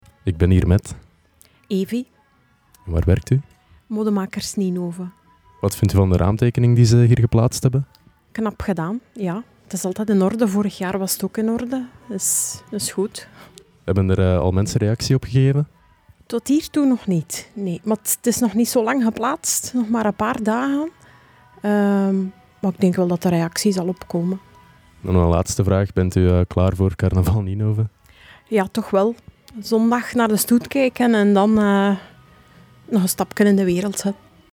interview-Modemakers_raamtekening.mp3